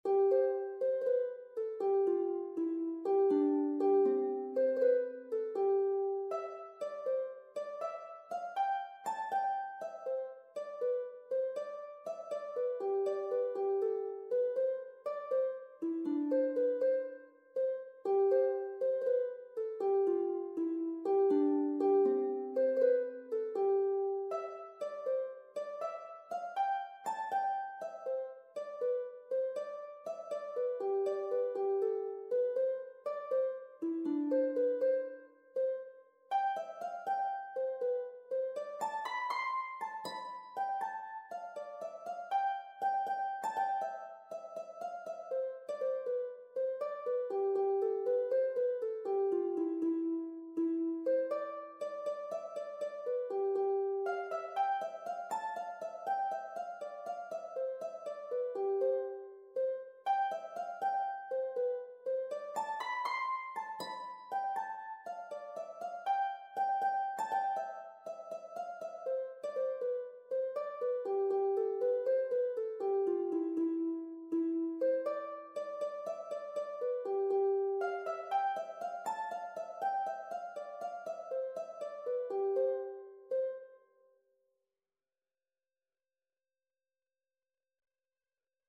Harp version
Harp  (View more Easy Harp Music)
Traditional (View more Traditional Harp Music)